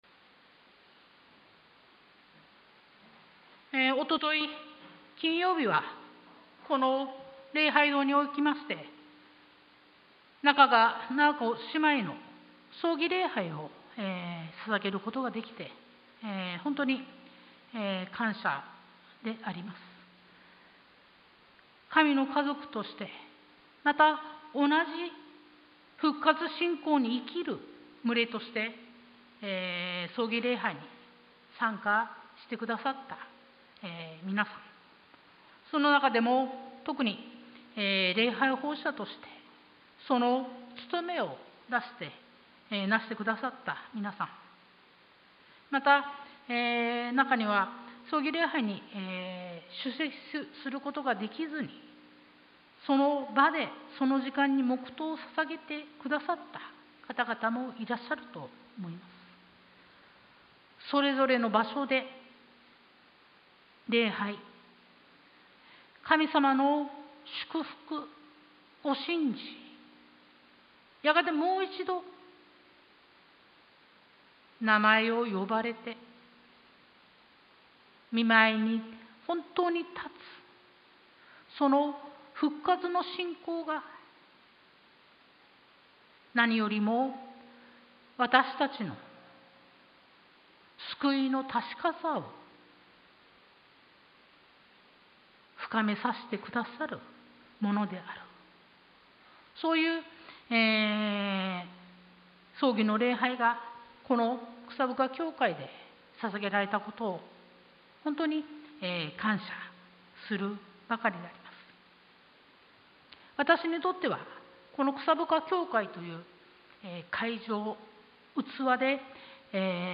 sermon-2022-07-31